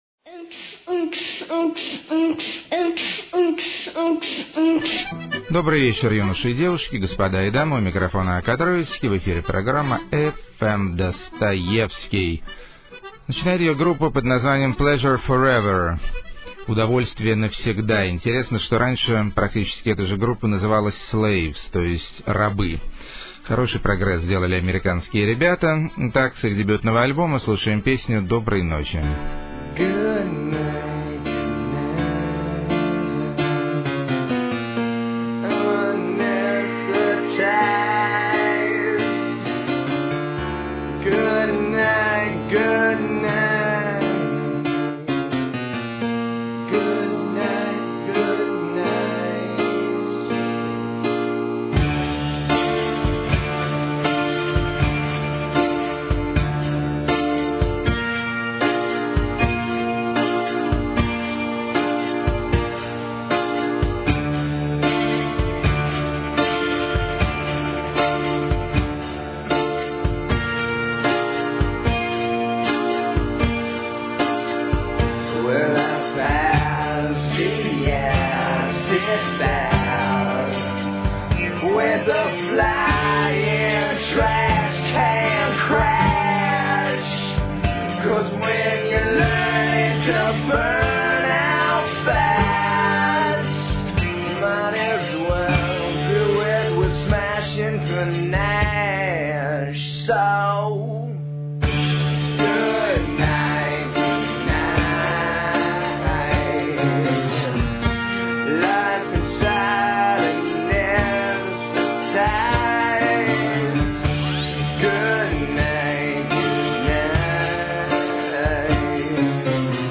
Редкий Случай Рэпа В Афро-традиции.
Никак Не Бэнд, А Очень Свеженький Хип-хоп.